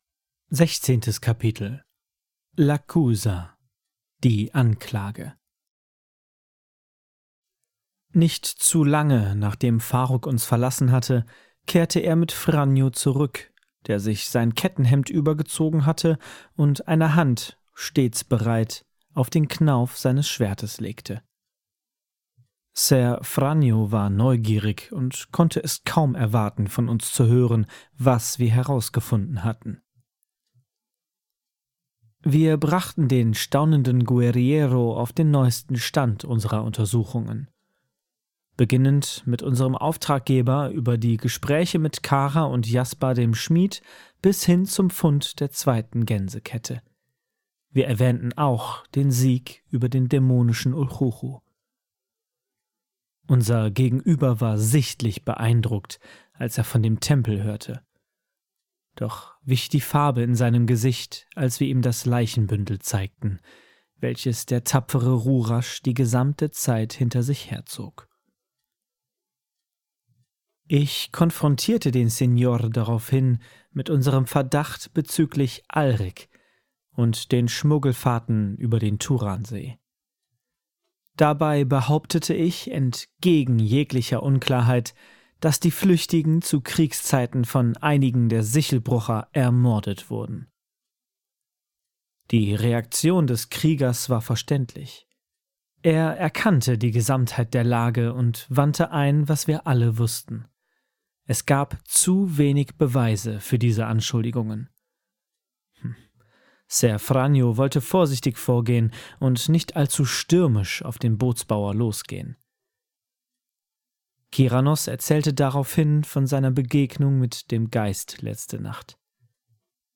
Die Calleano-Chroniken – Ein Hörbuch aus der Welt des schwarzen Auges